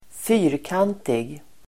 Uttal: [²f'y:rkan:tig]